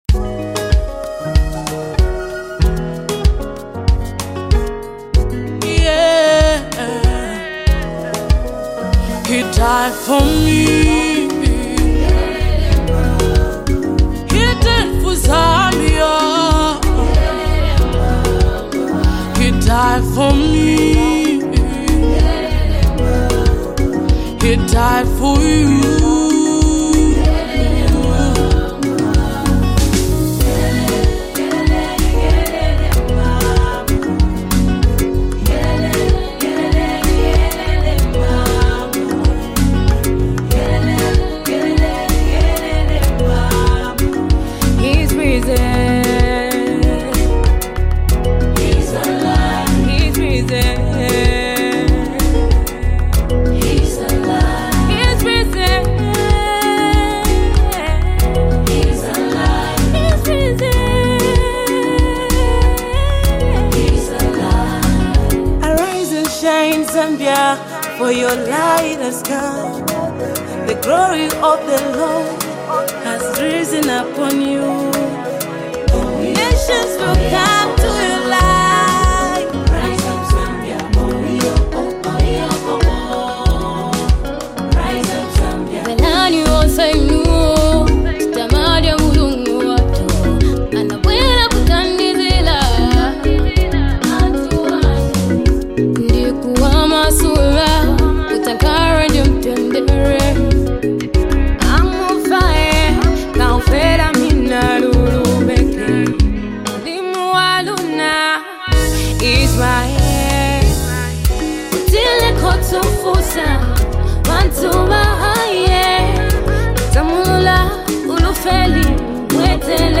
Zambian Gospel ministers